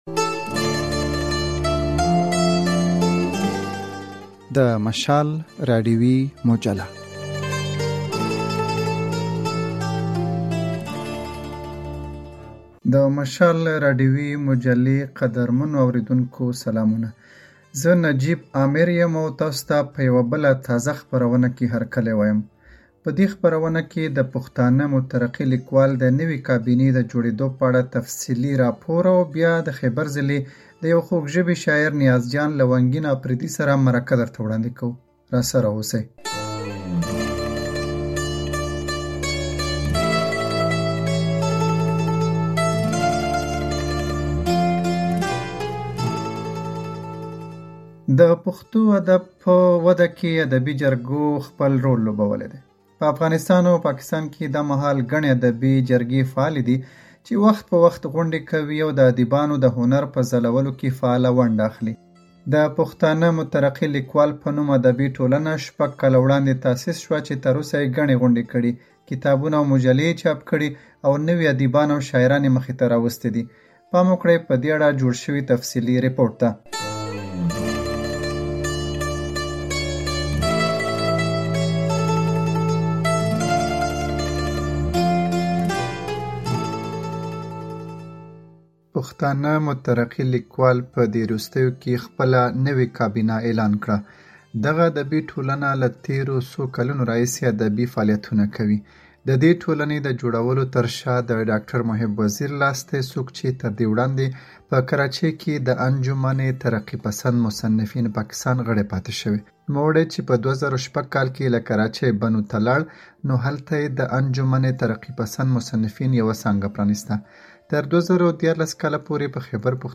د مجلې تازه ګڼه دلته واورئ